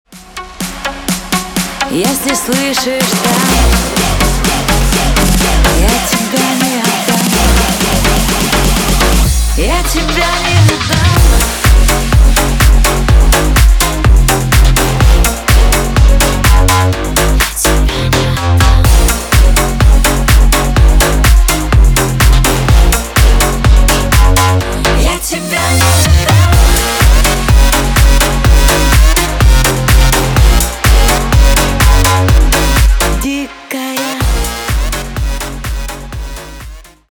на русском клубные на парня